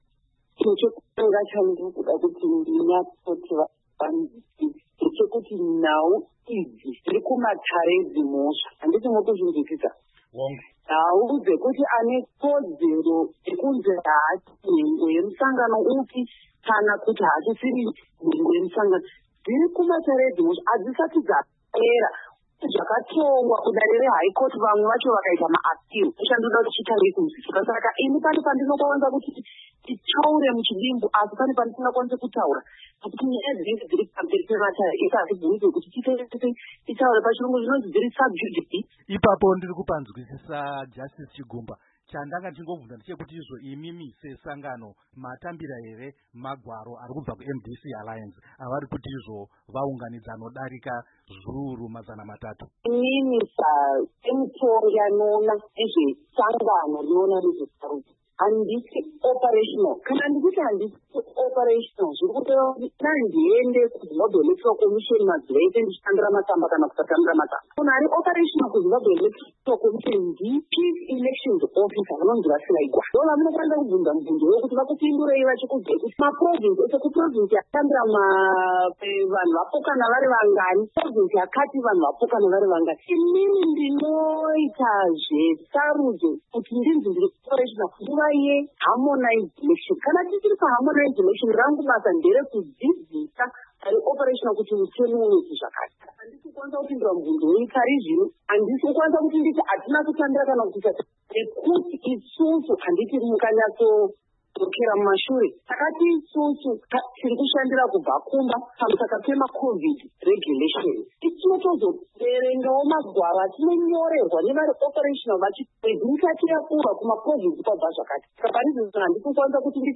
Hurukuro naJustice Priscilla Chigumba